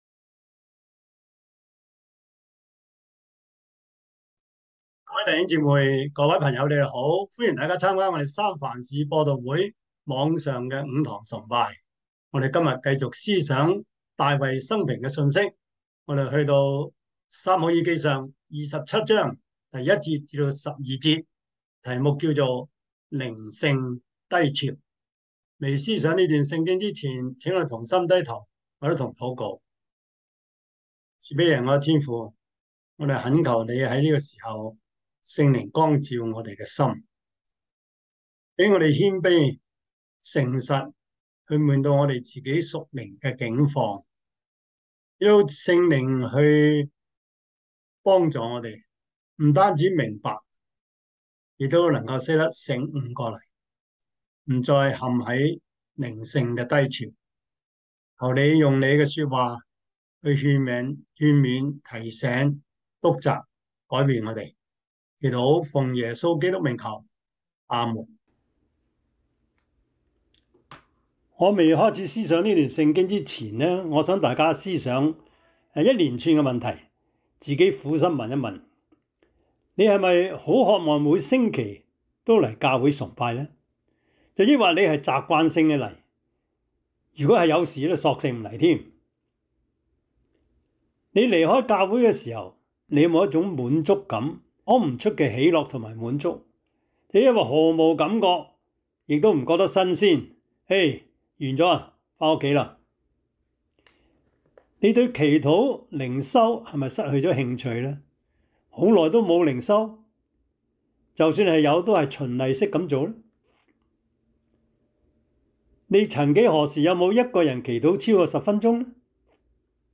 29:4-7 Service Type: 主日崇拜 撒 母 耳 記 上 27:1-12 Chinese Union Version
Topics: 主日證道 « 告別害怕 快樂人生小貼士 – 第二課 »